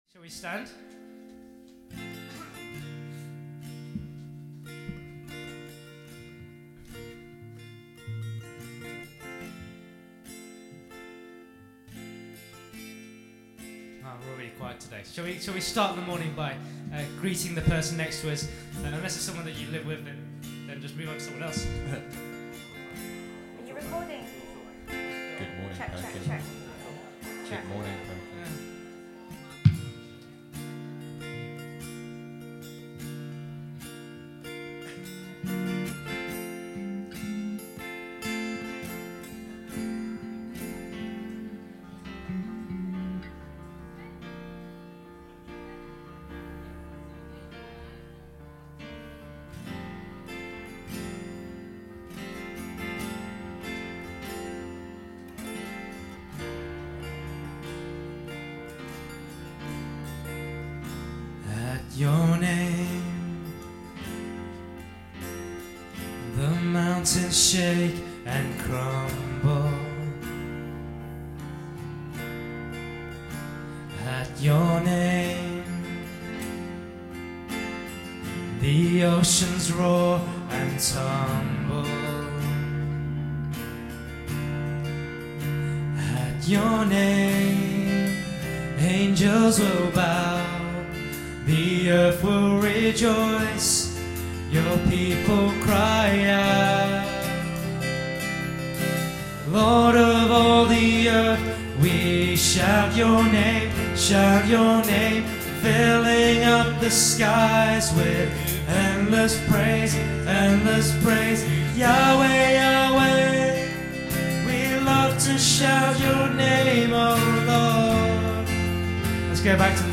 February 23 – Worship